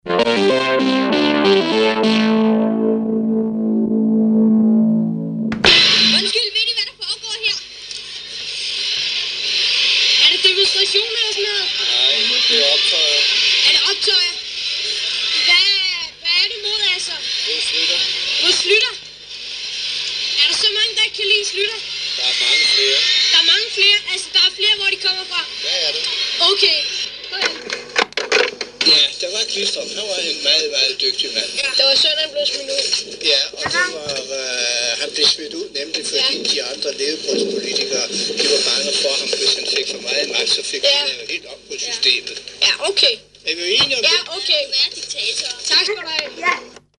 Billede Radio Flash støvede et par mærkelige eksistenser op ved New Kids On The Block Koncerten i København.